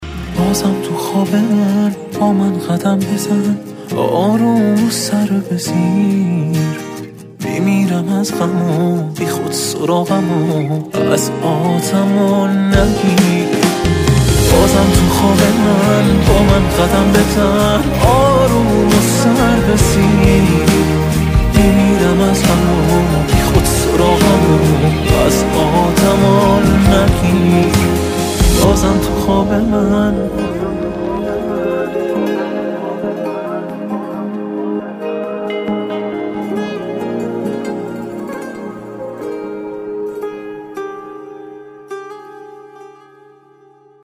آهنگ موبایل عاشقانه و با کلام